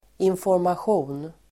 Uttal: [infårmasj'o:n]